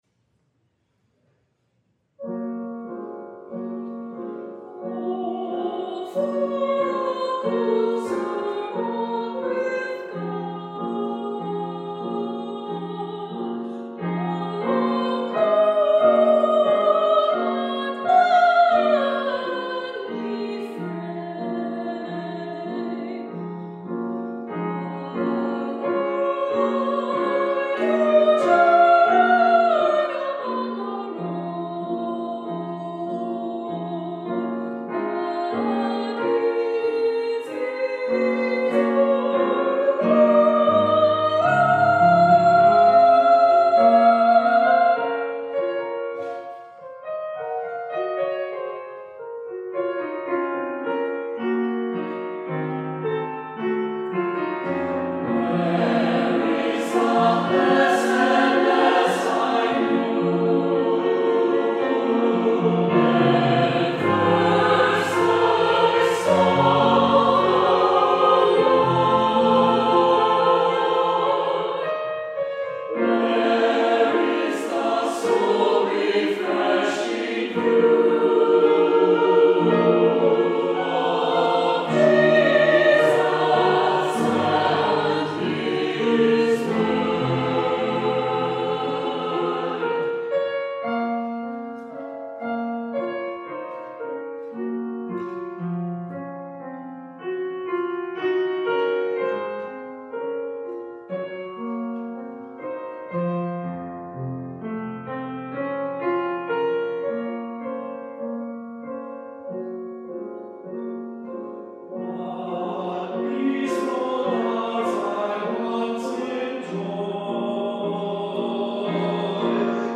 SAB chorus, piano